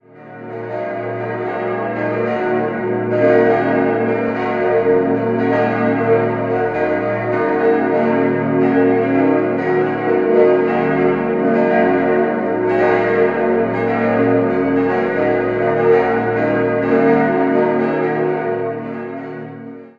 6-stimmiges Geläute: b°-es'-ges'-as'-b'-des'' Alle Glocken wurden im Jahr 2013 von Rudolf Perner in Passau gegossen.